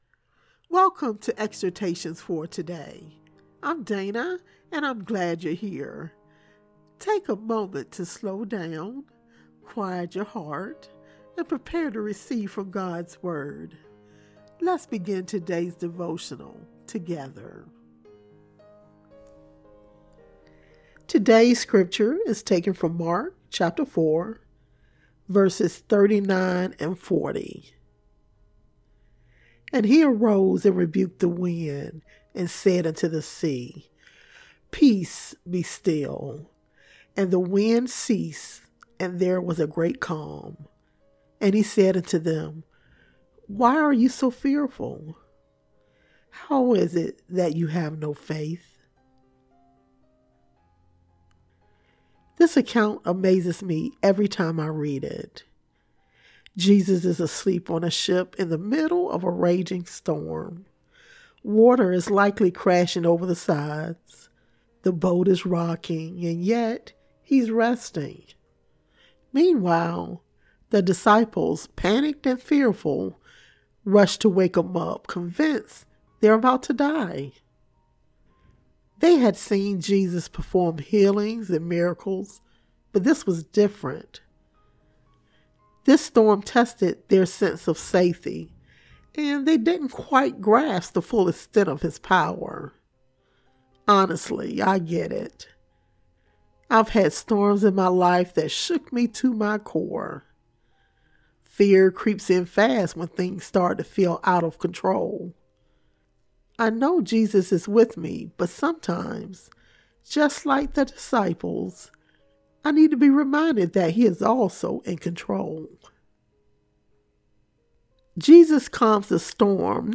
Daily Devotional